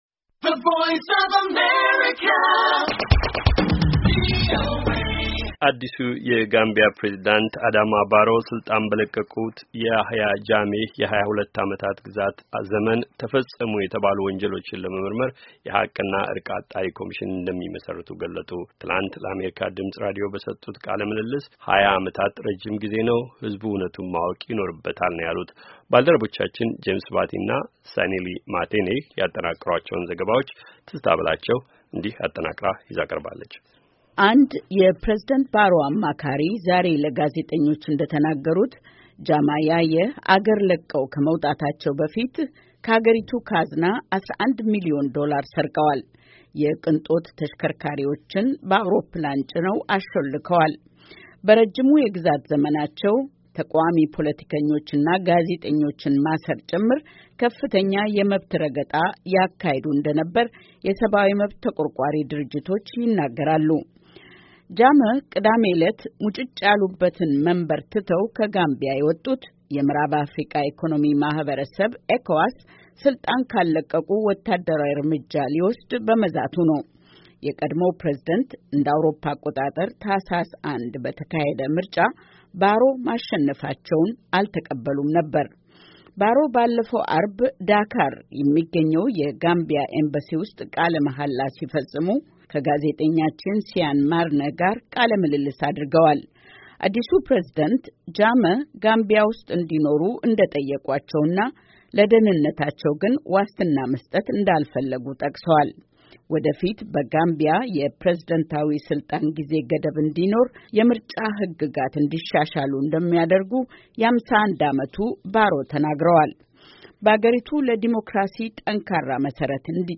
ትላንት ለአሜሪካ ድምፅ ራዲዮ በሰጡት ቃለ ምልልስ፣ 20 ዓመታት ረጅም ጊዜ ነው ሕዝቡ ዕውነቱን ማወቅ ይኖርበታል ብለዋል፡፡